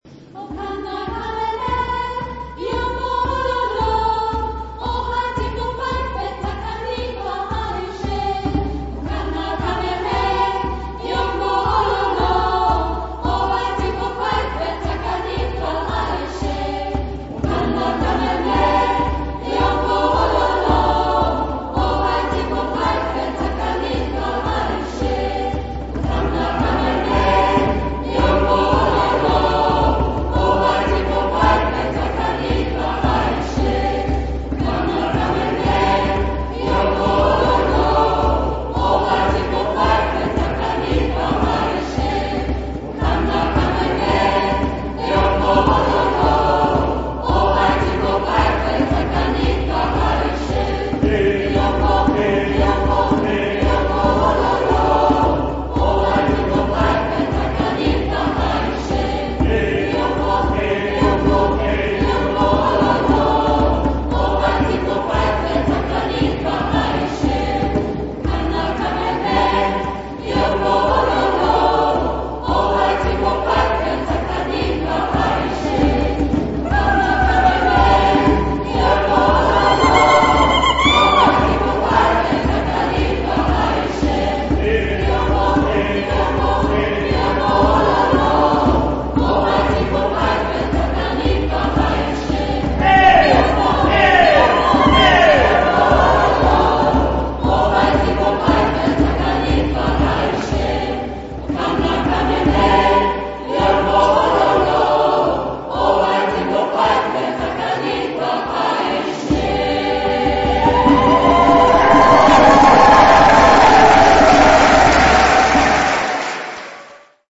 Jubiläumskonzert "D`Mess and More"
Wie man auch an einem warmen Sommerabend die Kirche voll bekommt, bewies eindrucksvoll der Chor unserer Pfarre.
Nach der Pause, in der man sich am liebevoll hergerichteten Buffett mit köstlichen Schinken-, Käse- und Wurststangerl stärken konnte, ging es mit den "Hits for the heart" weiter, wobei fast kein Auge trocken blieb.